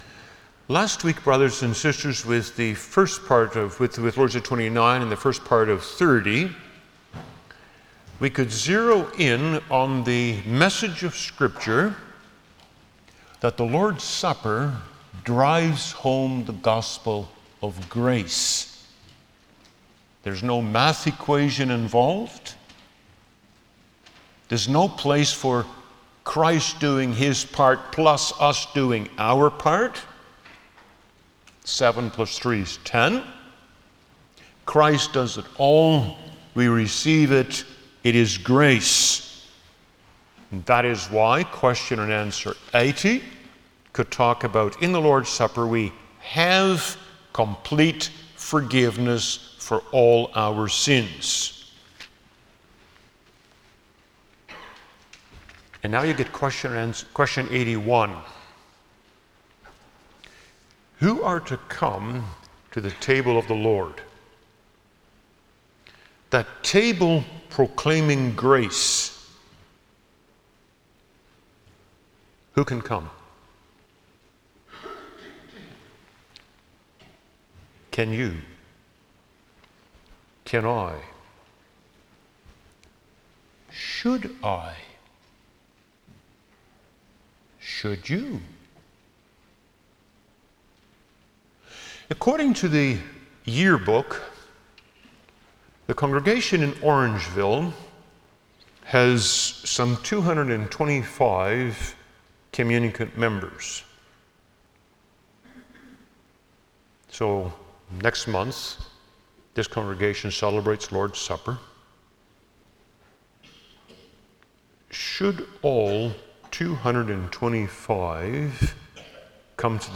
Service Type: Sunday afternoon
07-Sermon.mp3